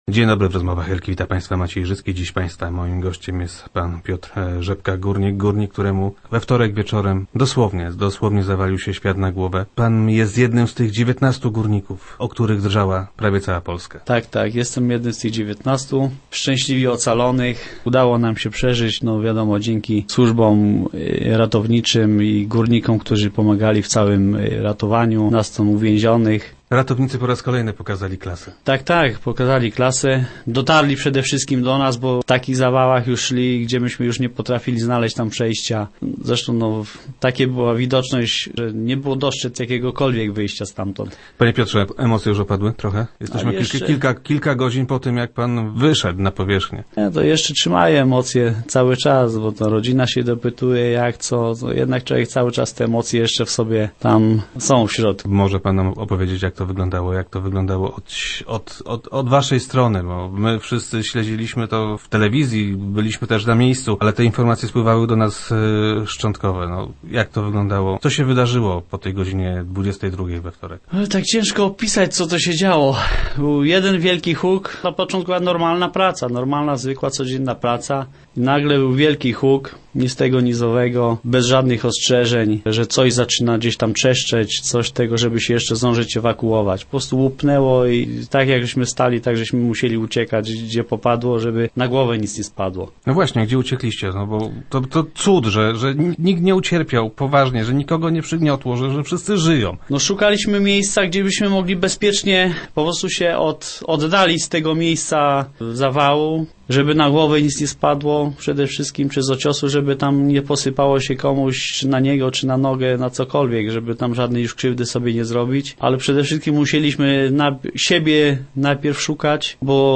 radio, głogów, głogowski, Lubin, lubiński, miedz, miedziowe, portal regionu, elka